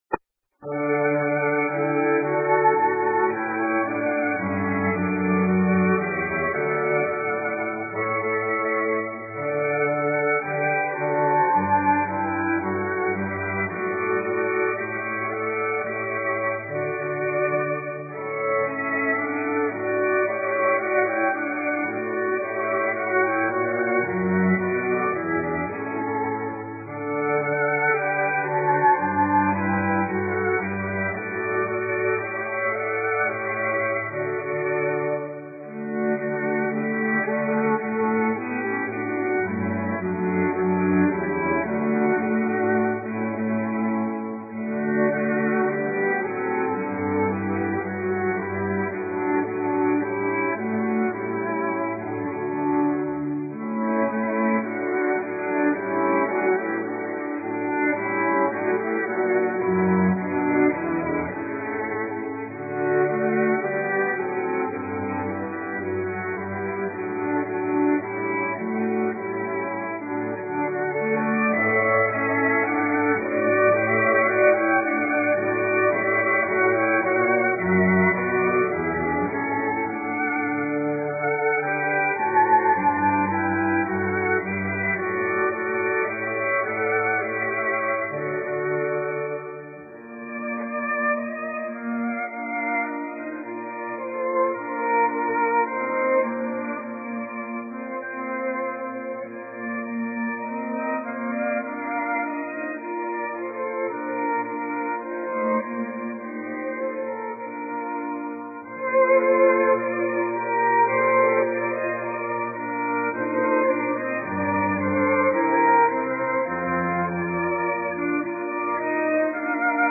ORQUESTAS
Instrumental